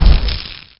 impact1.wav